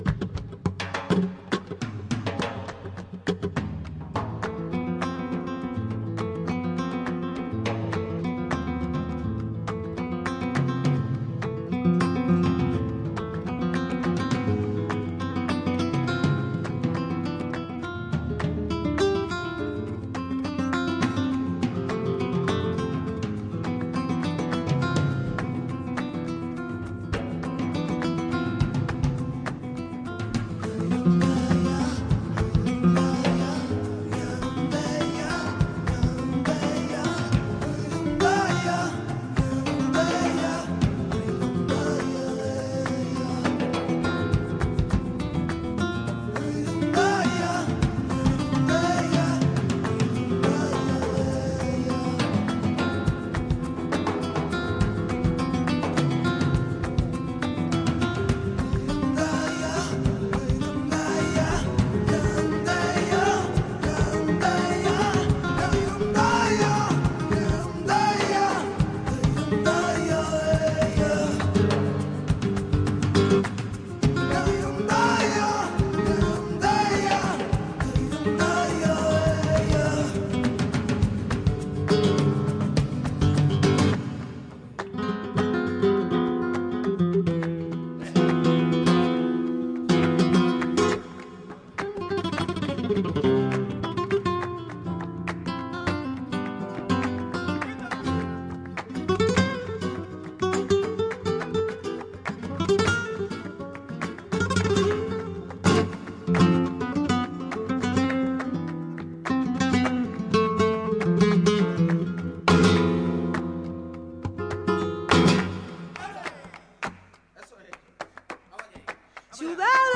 Durante la fantástica tertulia acontecida en Araboka hoy